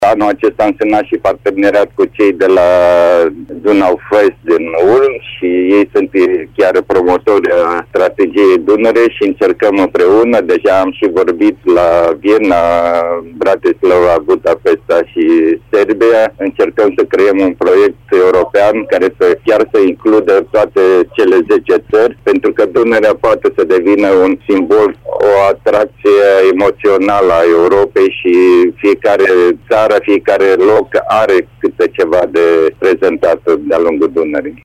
Supranumită şi „bicicleta Deltei”, canotca a stârnit interesul copiilor, a declarat în direct la Radio Timișoara, Ivan Patzaichin.